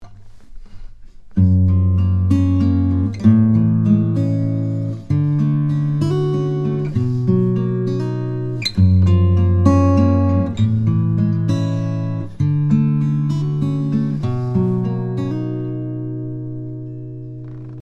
The main point is to hear what these 7th chords sound like together in a sequence.
7th chord progression example 6
Progression 6 chords are Gb Major 7, A Major 7, D Major 7, and B Major 7.